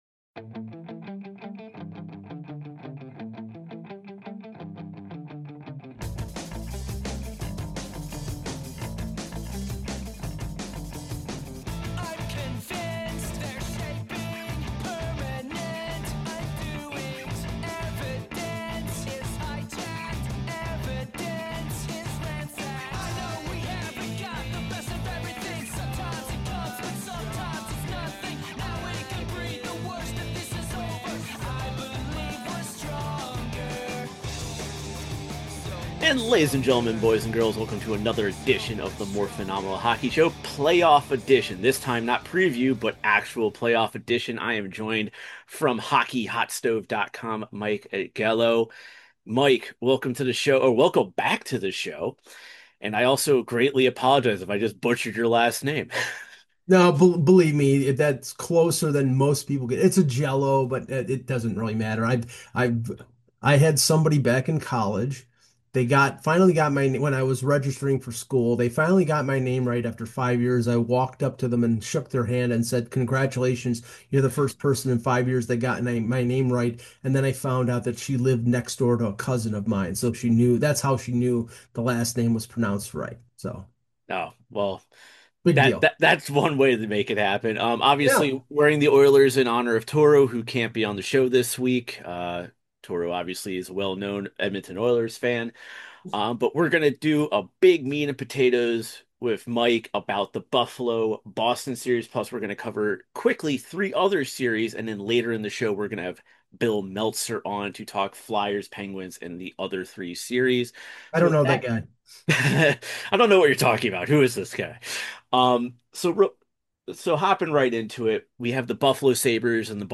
Ladies and Gentlemen welcome to The Morphinominal Hockey Show for some fun hockey conversations with actors from the Power Rangers and TMNT franchises